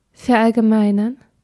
Ääntäminen
IPA: [fɛɐ̯ʔalɡəˈmaɪ̯nɐn]